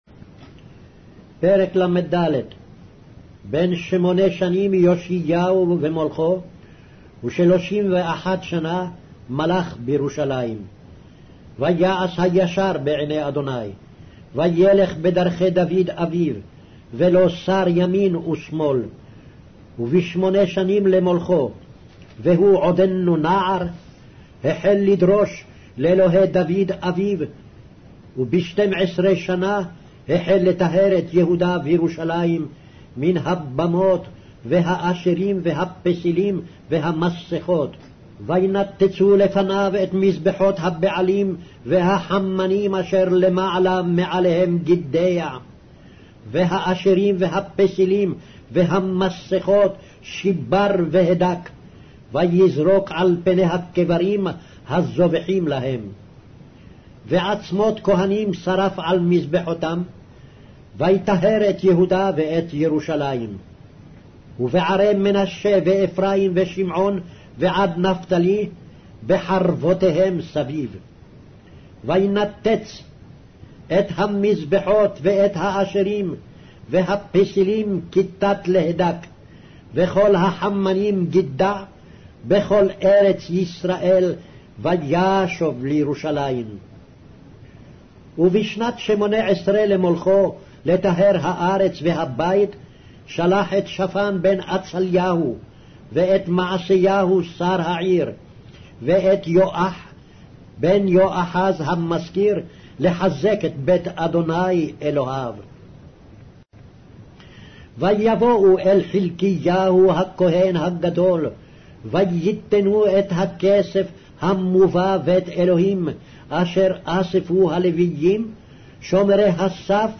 Hebrew Audio Bible - 2-Chronicles 32 in Web bible version